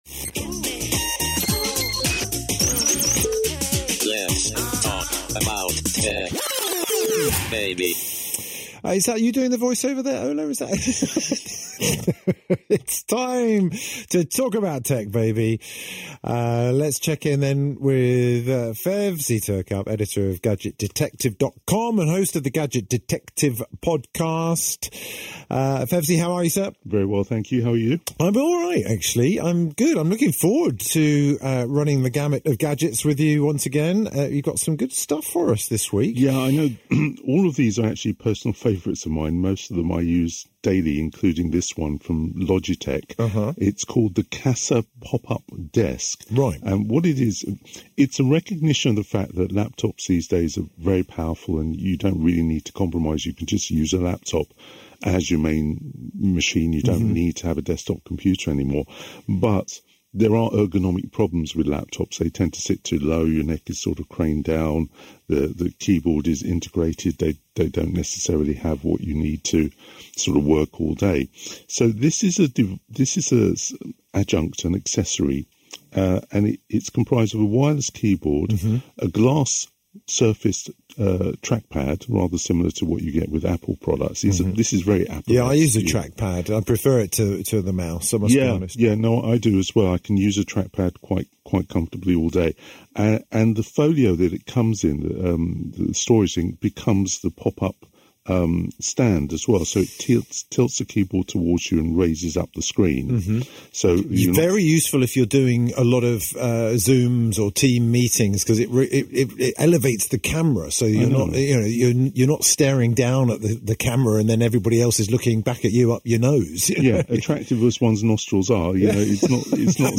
7th August 2025 - The Best Tech on BBC Radio London